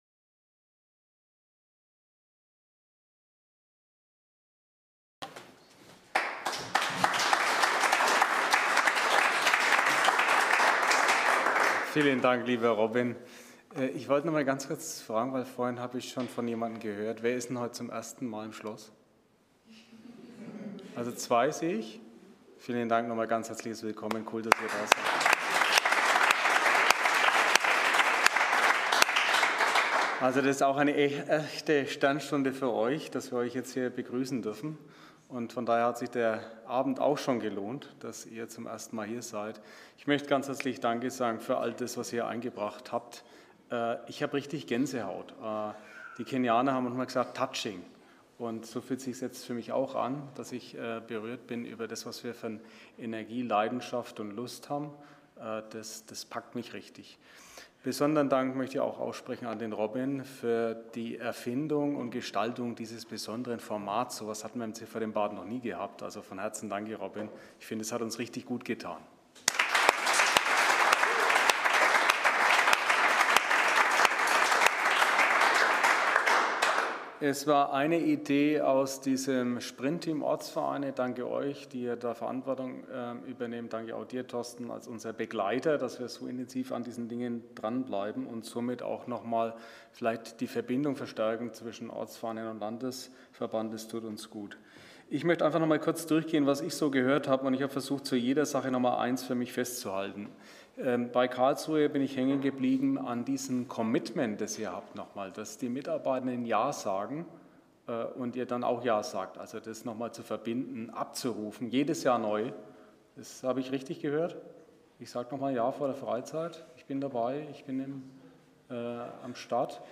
Themenbereich: Vortrag